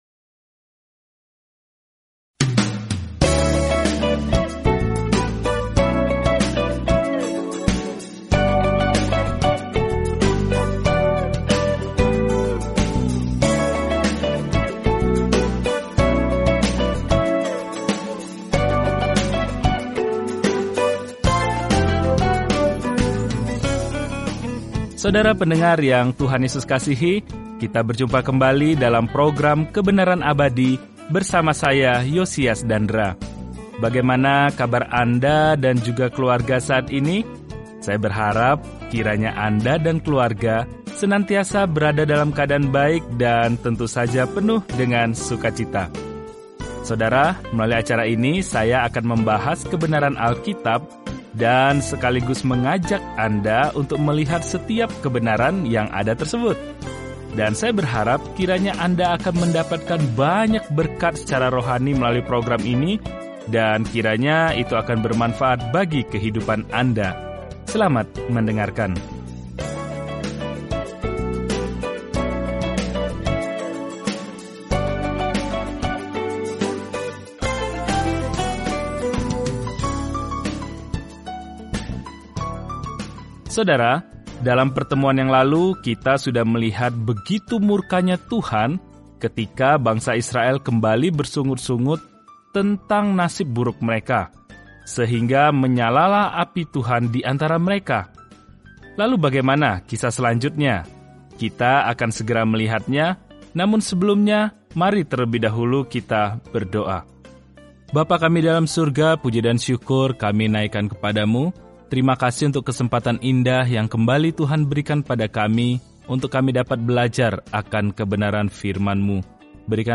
Firman Tuhan, Alkitab Bilangan 11:5-35 Bilangan 12 Hari 7 Mulai Rencana ini Hari 9 Tentang Rencana ini Dalam kitab Bilangan, kita berjalan, mengembara, dan beribadah bersama Israel selama 40 tahun di padang gurun. Jelajahi Numbers setiap hari sambil mendengarkan studi audio dan membaca ayat-ayat tertentu dari firman Tuhan.